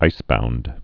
(īsbound)